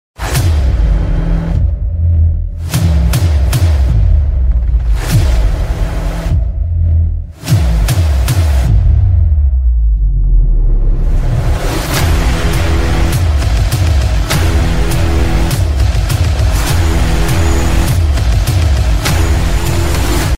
Cinematic Ink Splash Template | sound effects free download